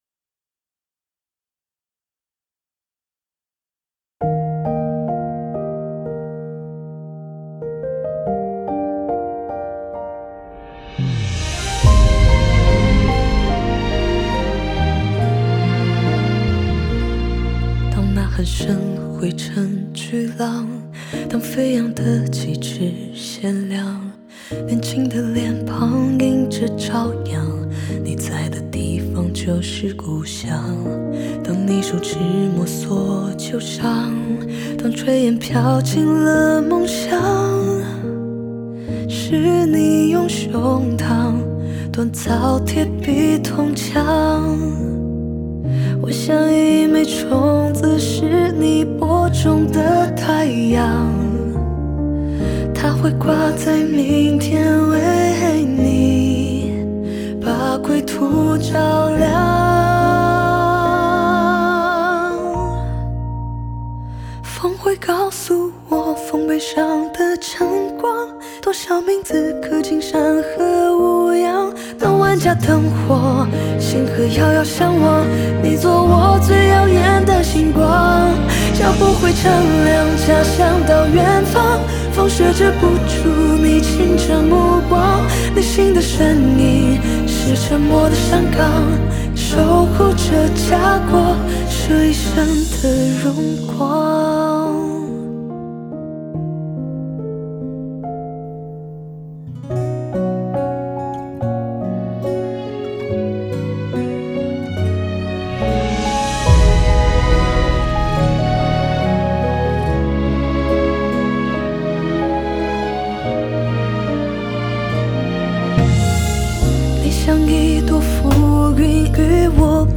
Ps：在线试听为压缩音质节选，体验无损音质请下载完整版
录音室：55TEC Studio